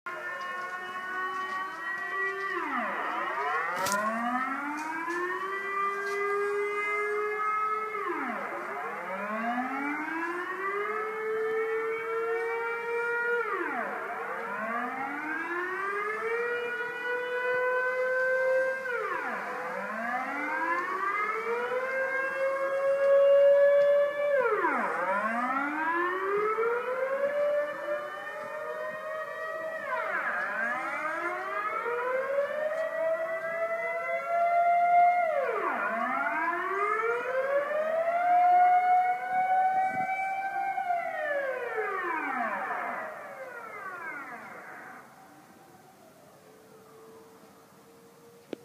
Zivilschutzsignal Holland In Holland hören Sie am ersten Montag eines jeden Monats mittags um genau 12.00 Uhr etwa eine Minute lang einen Signalton, eine auf und abschwellende Sirene, die Sie vielleicht irritiert - das muss Sie aber nicht beunruhigen. Es ist ein Test für den "Ernstfall", dieses Signal würde bei einem Zwischenfall der für den Schutz der Zivilbevölkerung von Belang ist, zur Warnung eingesetzt..